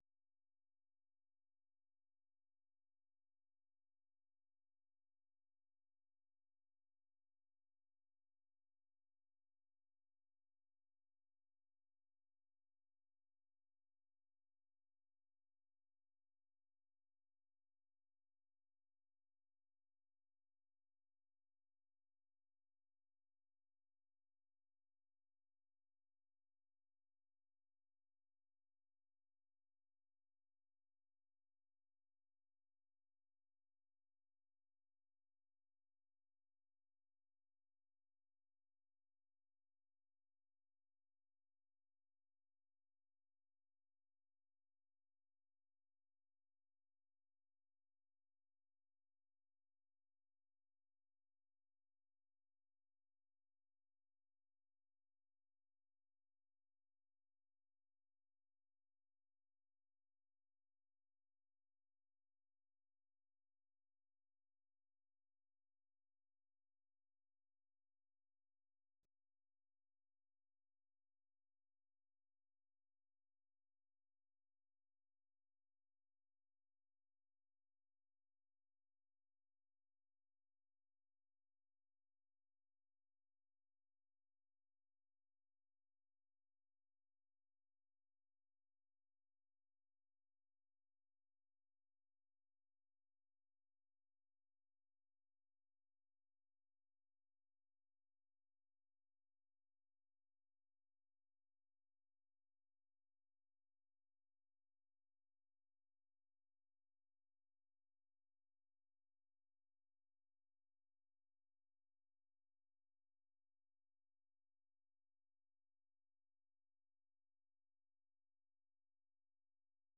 Tabale ka laɲini ye ka kɛ Mali den millions caman de kuman da’a ye ani ka u lakunu . Jɛmukan mun bɛ kɛ kabi an ka kunanfoni di bolofara so la , Bamako, bɛ kibaruyaw guɛlɛ labanw di, kow ɲin ŋini.